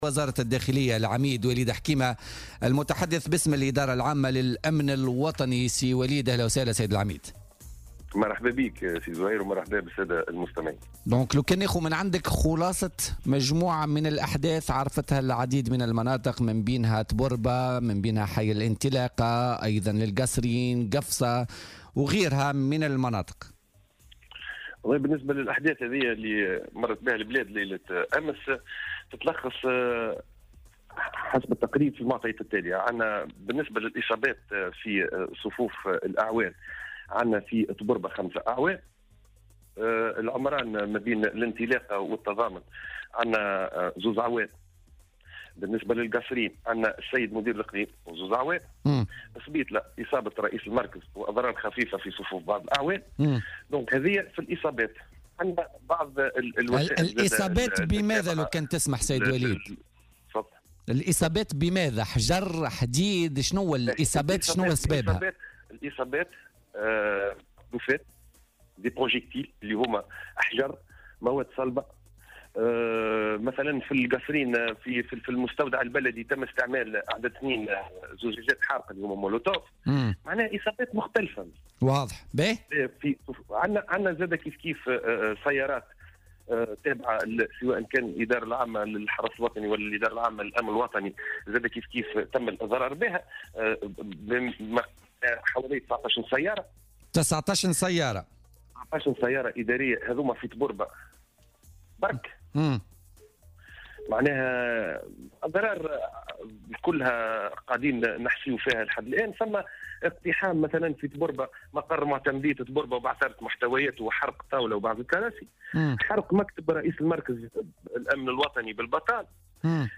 في اتصال هاتفي مع "الجوهرة أف أم" ببرنامج "بوليتيكا"